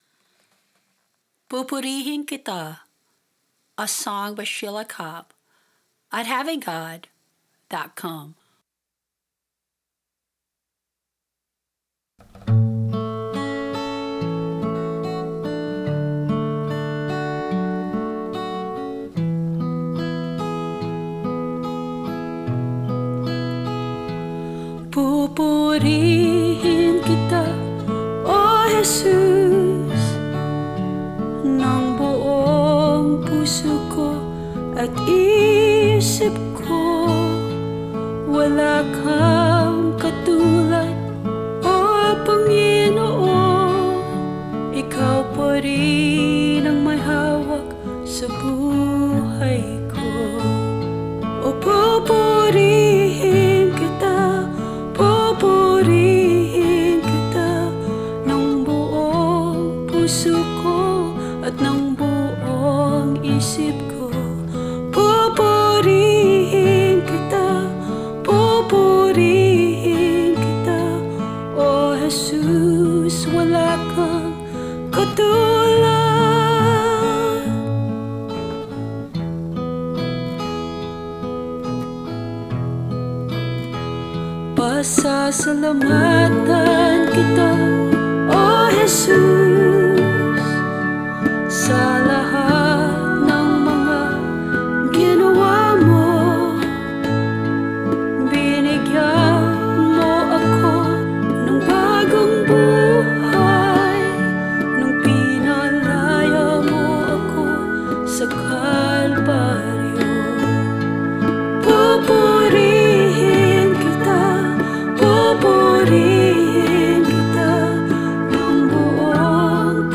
Vocals, guitar, bass and bongos
Organ, keyboard and strings